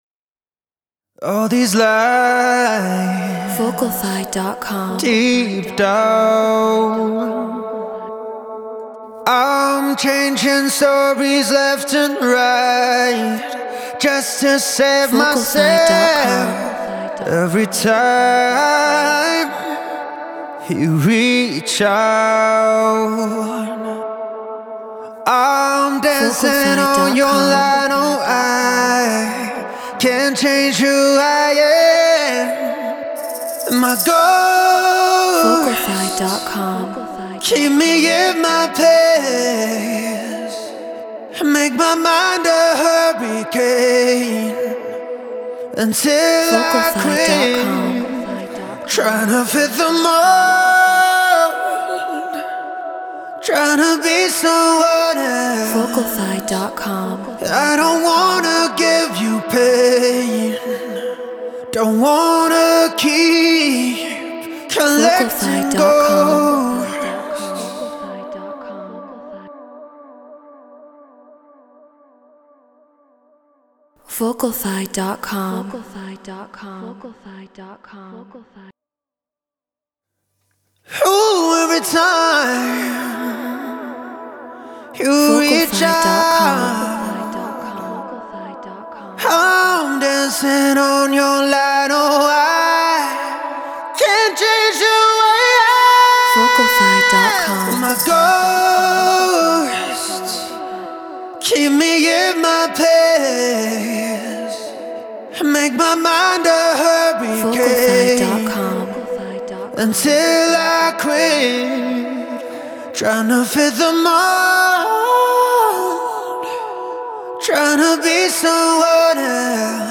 Afro House 120 BPM Bmin
Human-Made